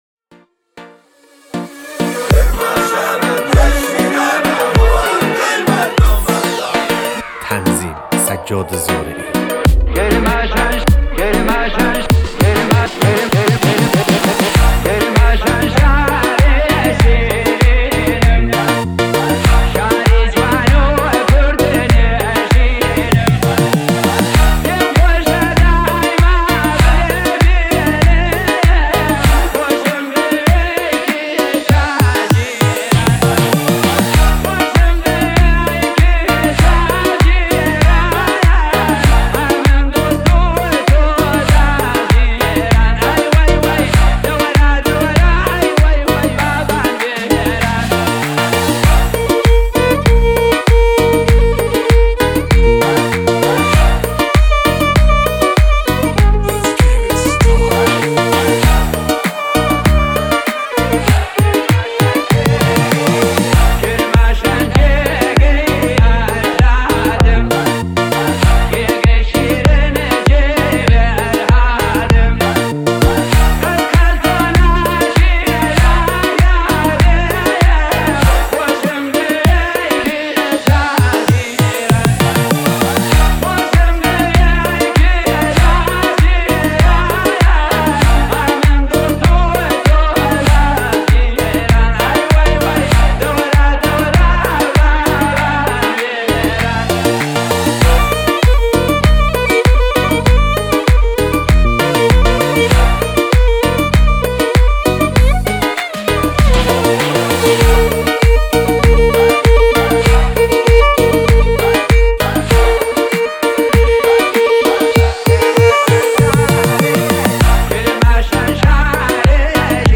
741 بازدید ۲۸ اسفند ۱۴۰۲ ریمیکس , ریمیکس کردی